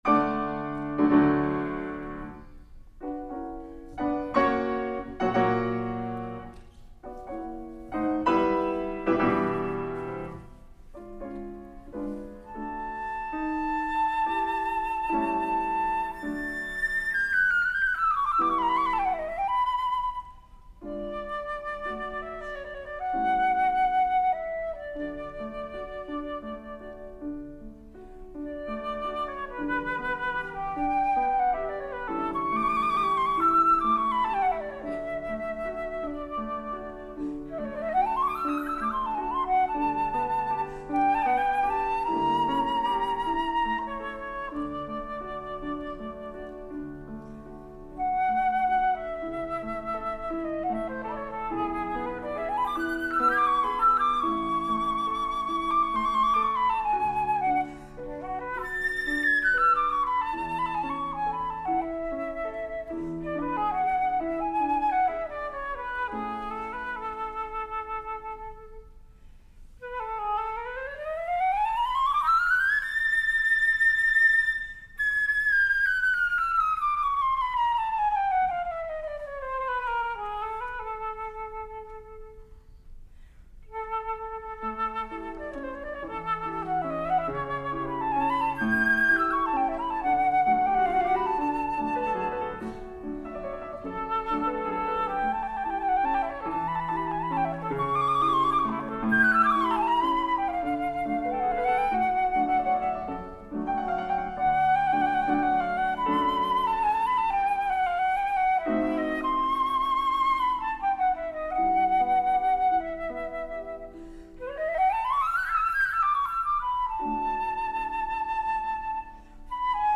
Her flute is a Yamaha heavy Wall, 14K 892 Julius Baker Model Flute with a “K” Cut Headjoint.